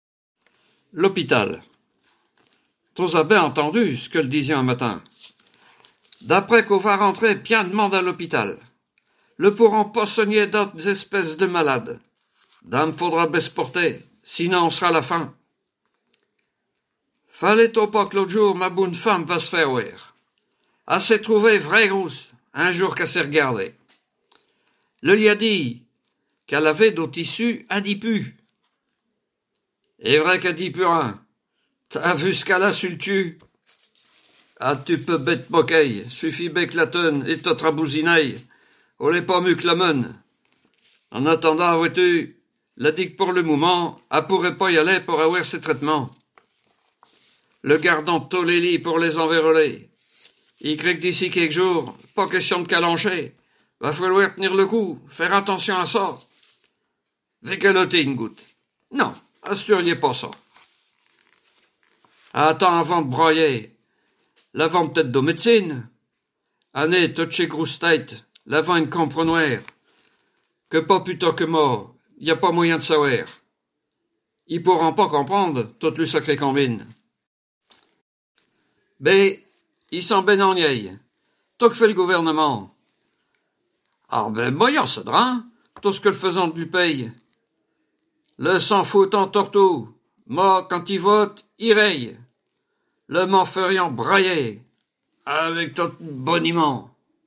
Langue Patois local
Genre poésie
Poésies en patois